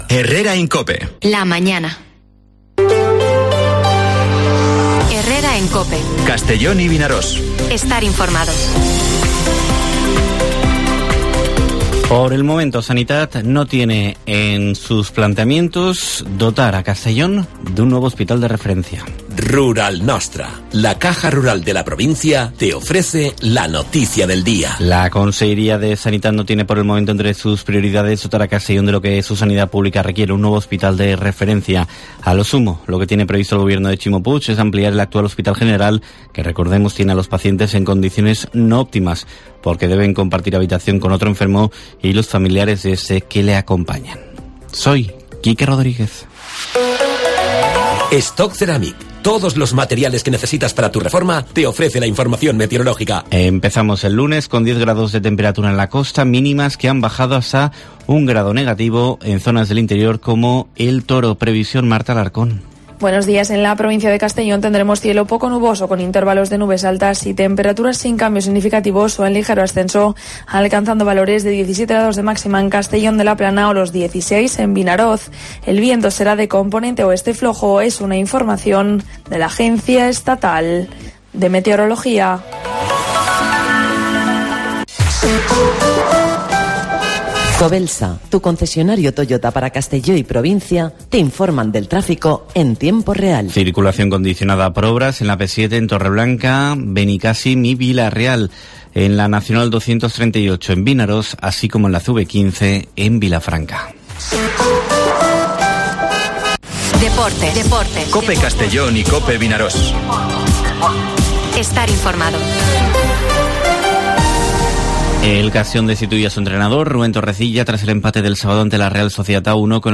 Informativo Herrera en COPE en la provincia de Castellón (19/12/2022)